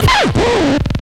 DJ  OOOOPPS.wav